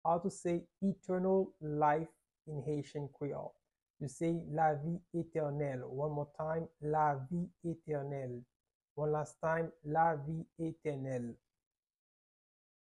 How to say "Eternal Life" in Haitian Creole - "Lavi etènèl" pronunciation by a Haitian tutor
“Lavi etènèl” Pronunciation in Haitian Creole by a native Haitian can be heard in the audio here or in the video below:
How-to-say-Eternal-Life-in-Haitian-Creole-Lavi-etenel-pronunciation-by-a-Haitian-tutor.mp3